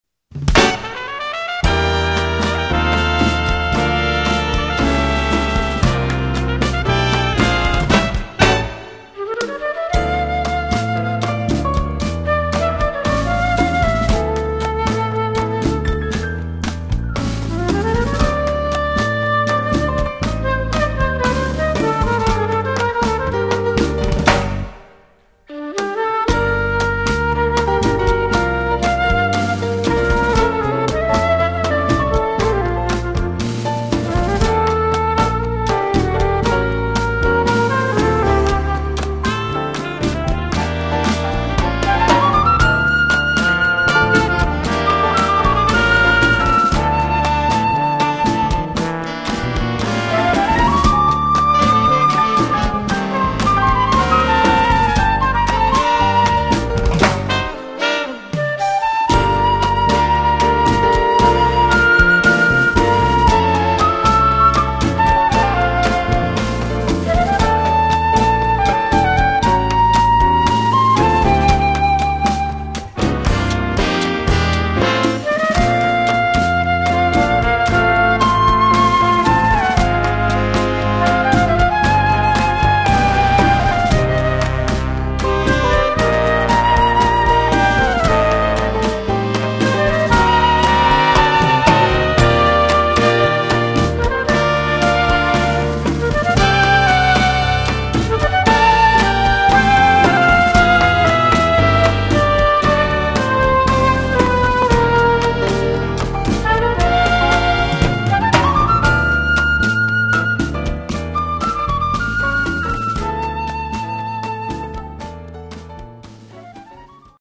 Category: big band
Style: danzón
Solos: trumpet 3, flute, piano
Instrumentation: big band (4-4-5, rhythm (4)
*doubles: alto 2 on flute